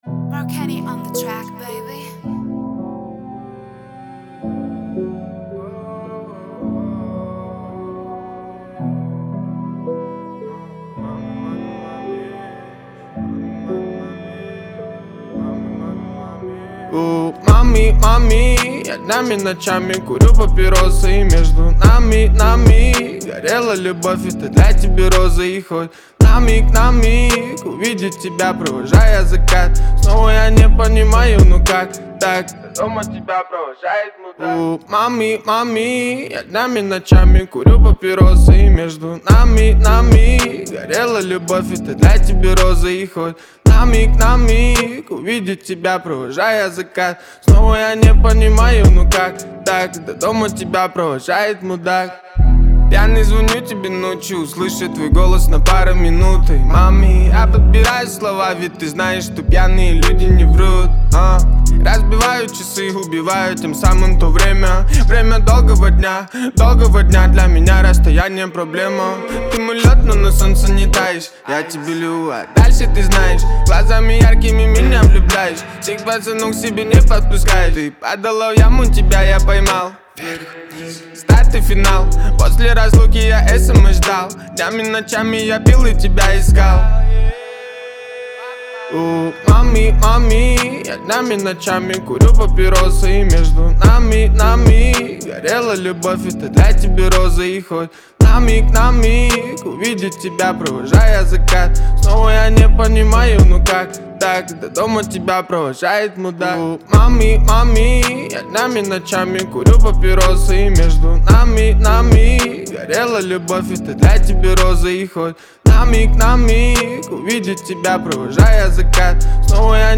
это зажигательная композиция в жанре латин-поп